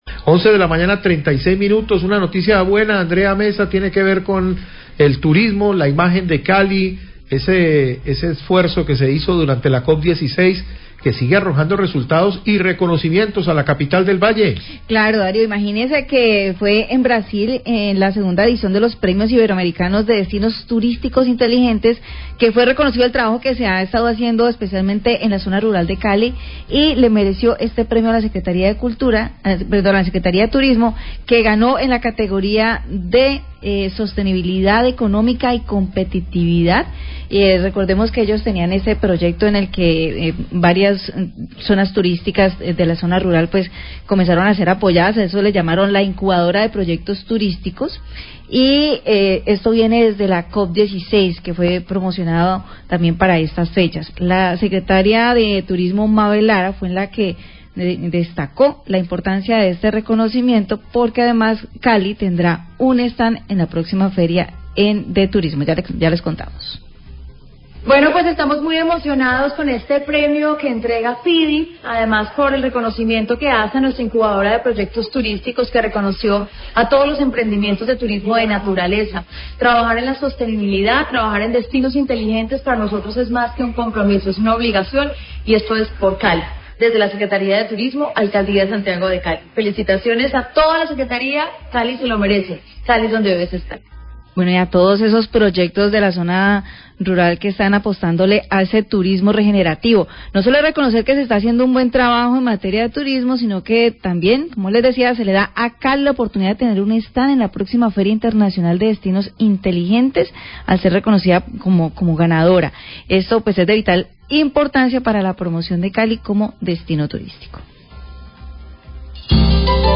Radio
Mabel Lara, Secretaria de Turismo de Cali, habla premio Iberoamericano de Destinos Turísticos Inteligentes que se ganó Cali por el trabajo de incubadoras de proyectos turísticos en zona rural de la ciudad.